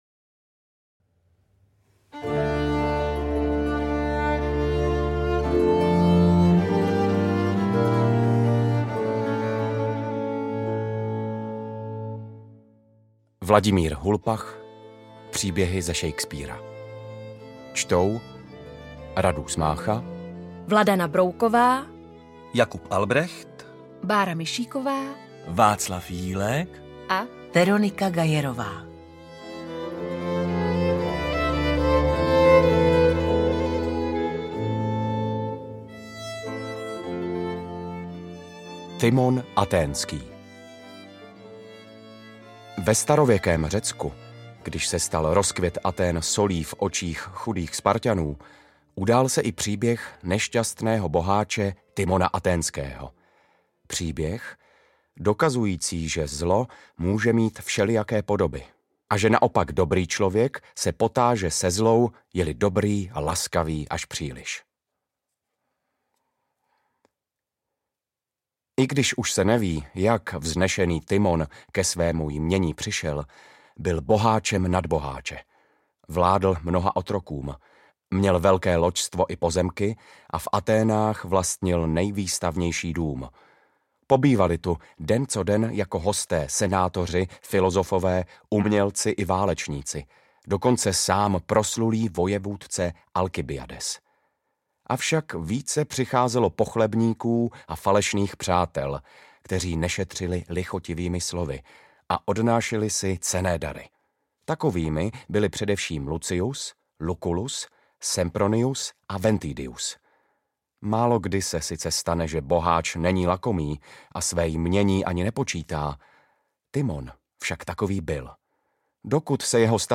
To nejlepší ze Shakespeara nyní poprvé jako audiokniha William Shakesepare, jehož dramatickému dílu je věnována tato zvuková kniha, patří mezi velikány světové literatury.